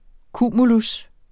Udtale [ ˈkuˀmulus ]